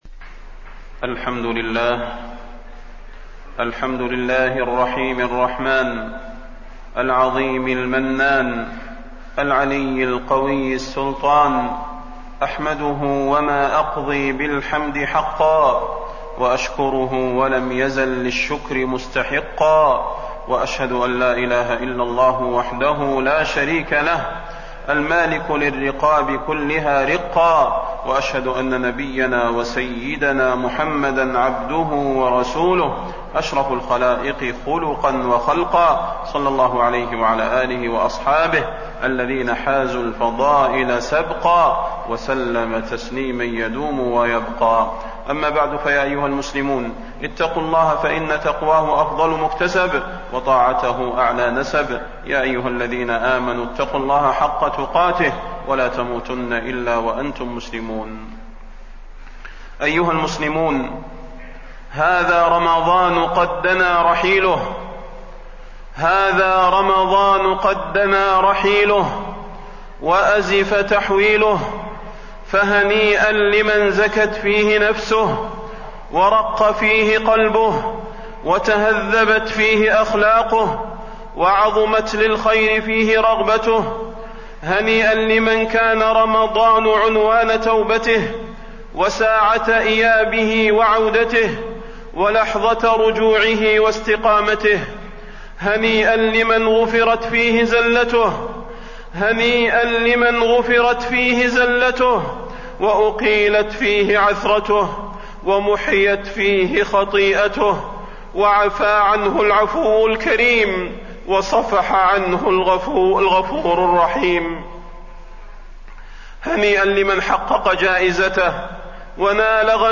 تاريخ النشر ٢٨ رمضان ١٤٣٠ هـ المكان: المسجد النبوي الشيخ: فضيلة الشيخ د. صلاح بن محمد البدير فضيلة الشيخ د. صلاح بن محمد البدير زكاة الفطر وصلاة العيد